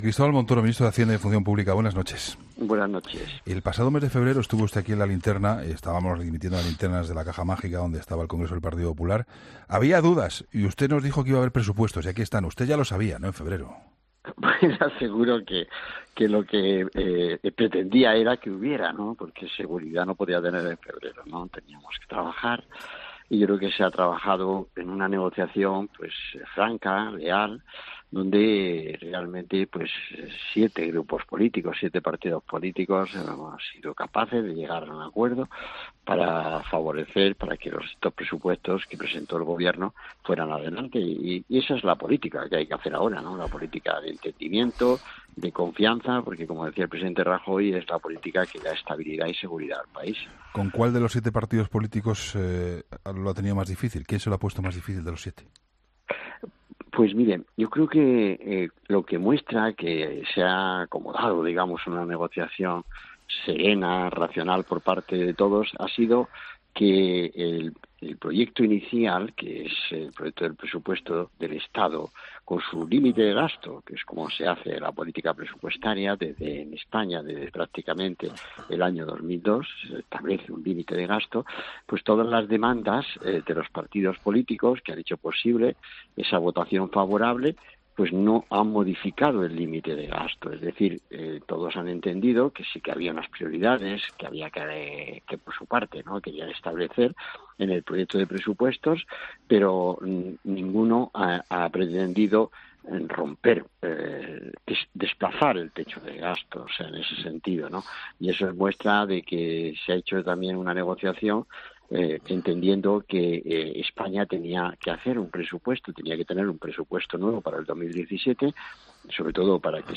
Entrevista a Cristóbal Montoro